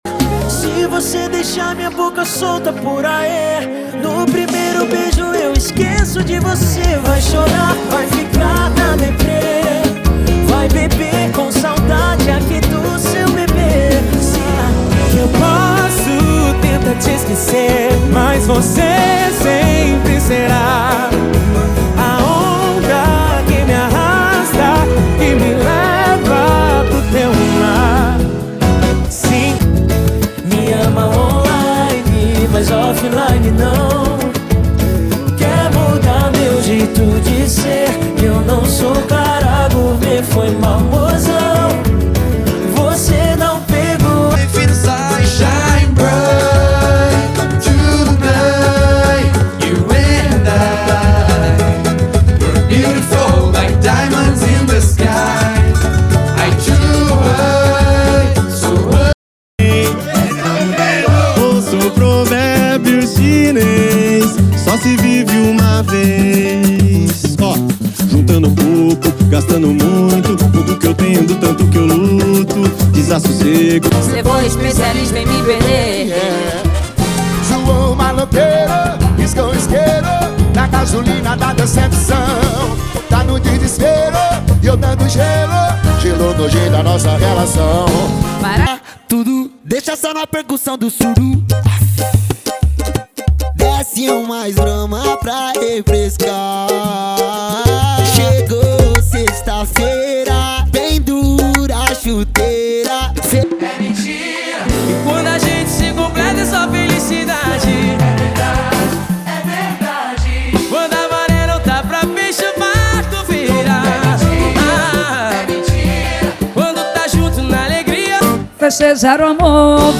• Pagode e Samba = 50 Músicas